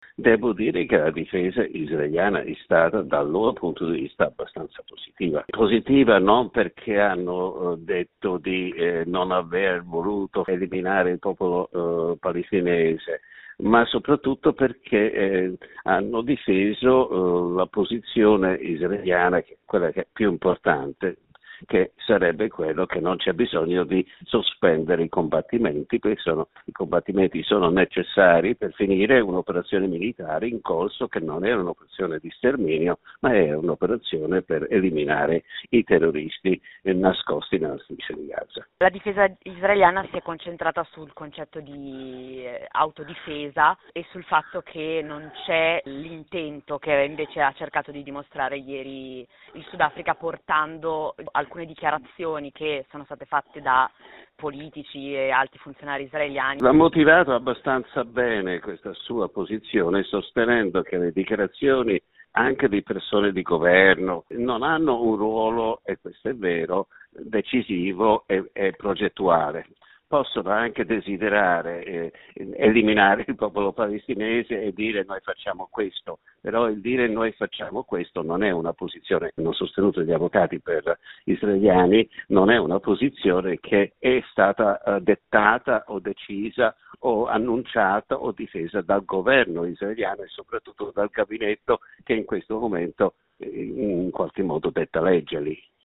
giornalista a lungo inviato in Israele